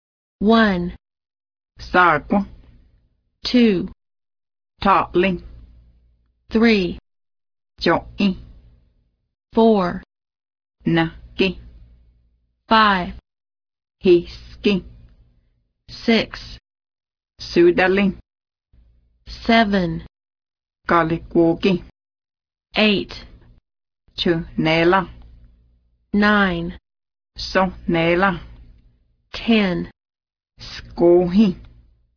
Cherokee Audio Lessons